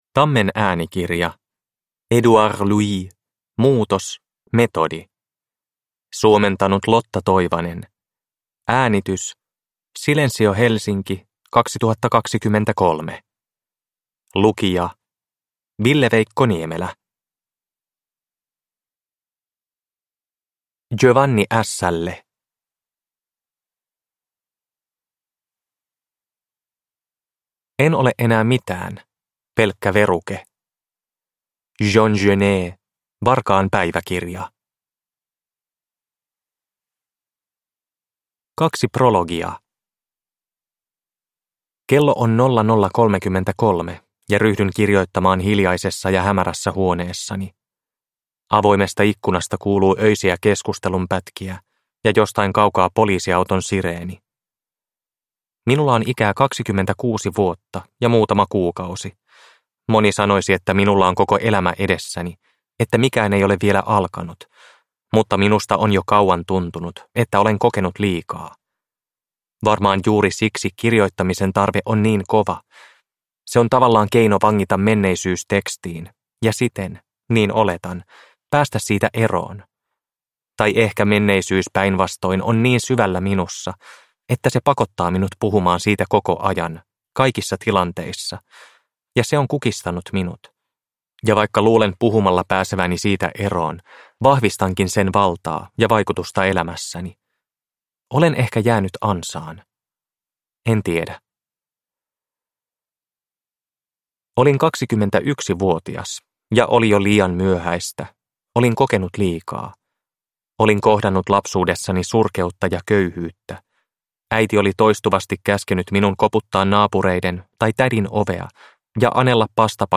Muutos: metodi – Ljudbok – Laddas ner